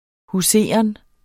Udtale [ huˈseˀʌn ]